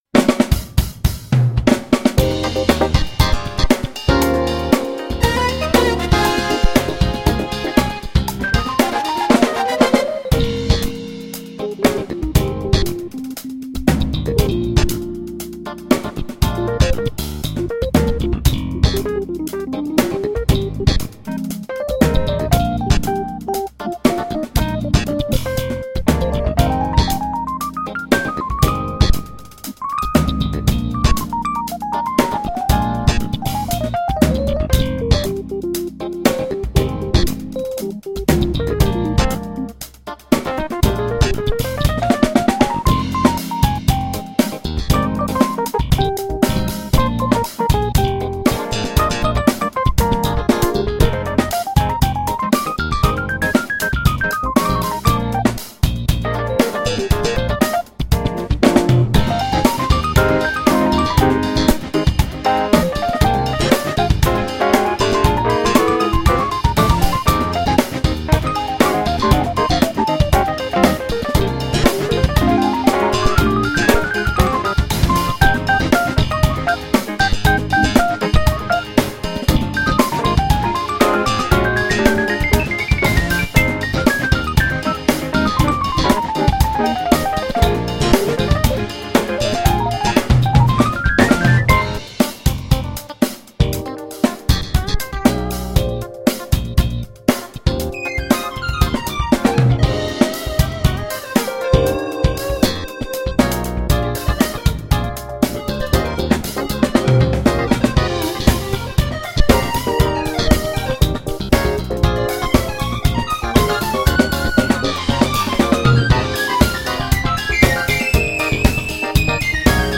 ARRANGER + VSTi in realtime WOW just HEAR this DEMO | General Arranger Keyboard Forum | Synth Zone Forums
Just selected one funky style, pressed the START button, played chords left hand, and melody right hand, to let you know what we can do in realtime, not after hours of studio work.
vArranger + VSTi Funky1.mp3